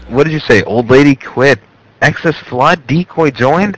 Garble